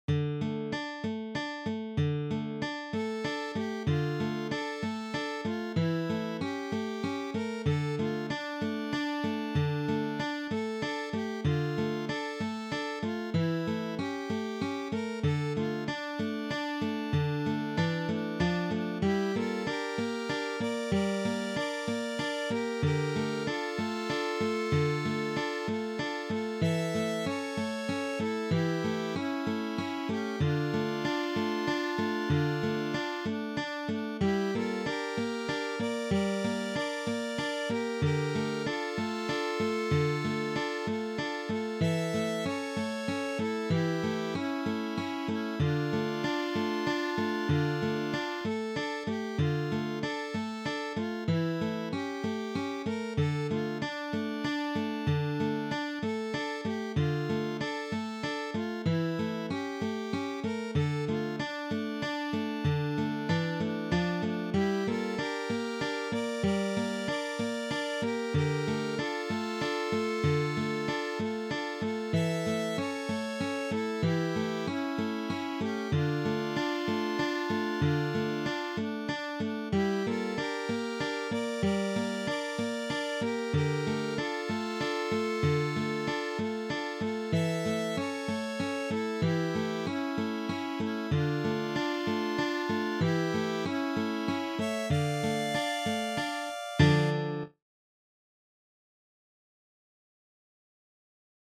(Canto popolare